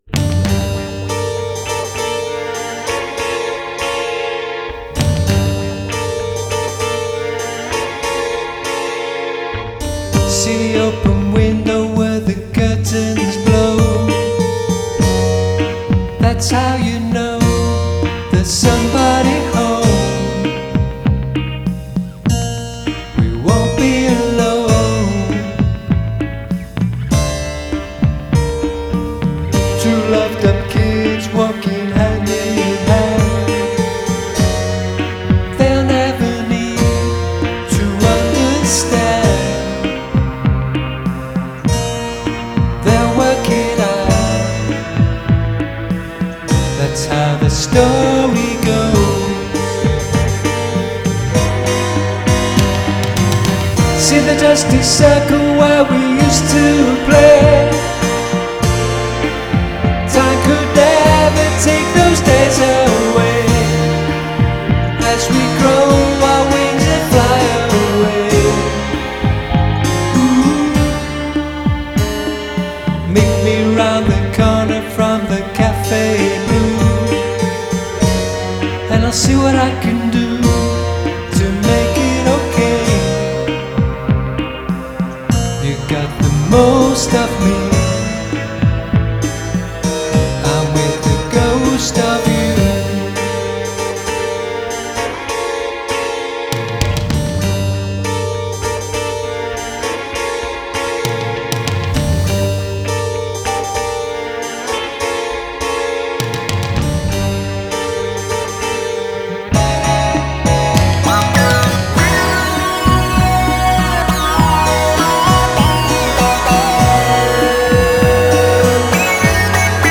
the joys of multi-tracking in your home studio
Reviews have likened his work to shades of Sunshine Pop.